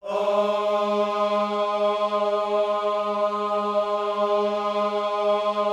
OHS G#3D  -L.wav